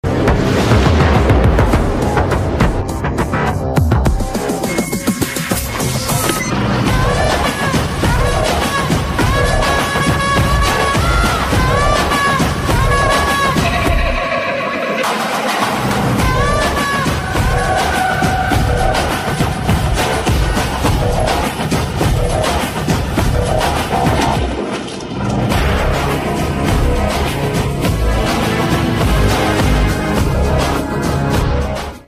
cinematic teaser
mass bgm